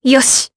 Tanya-Vox_Happy4_jp.wav